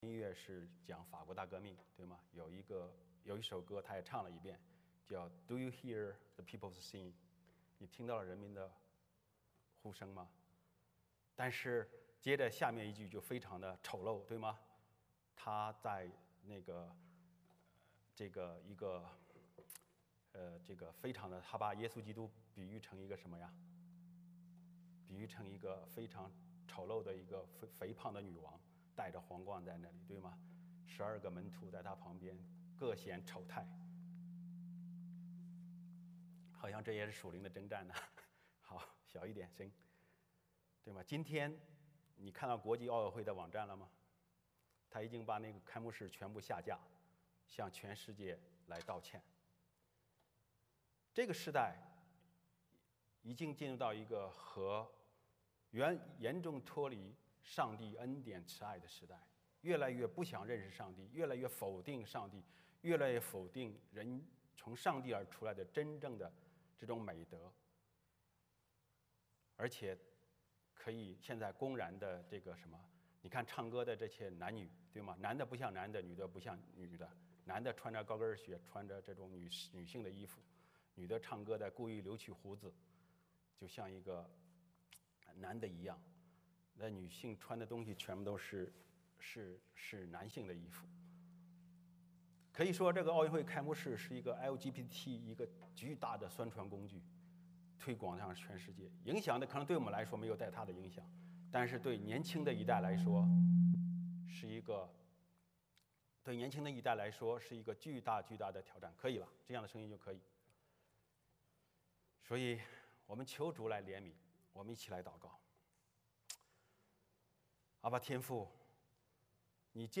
欢迎大家加入我们国语主日崇拜。
1 Service Type: 主日崇拜 欢迎大家加入我们国语主日崇拜。